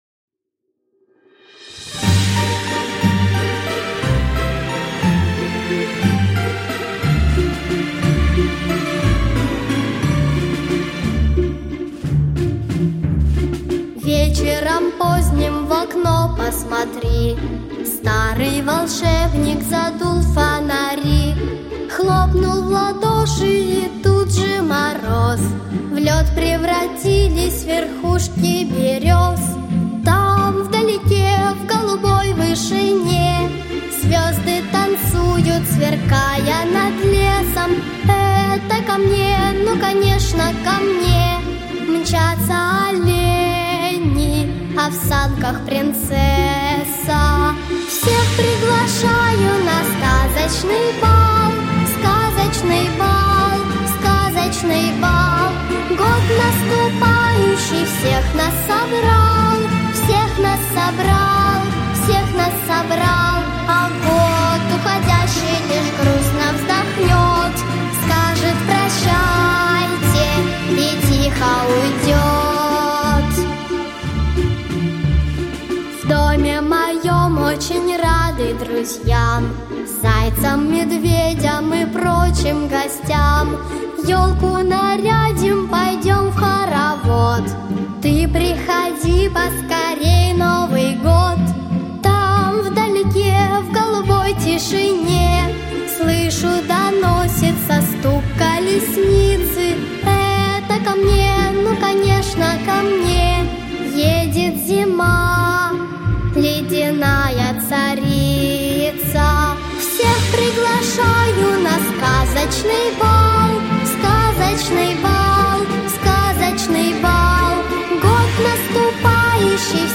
🎶 Детские песни / Песни на Новый год 🎄 / Песни про Зиму 🥶